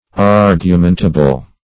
Meaning of argumentable. argumentable synonyms, pronunciation, spelling and more from Free Dictionary.
Search Result for " argumentable" : The Collaborative International Dictionary of English v.0.48: Argumentable \Ar`gu*men"ta*ble\ (-men"t[.a]*b'l), a. [L. argumentabilis.]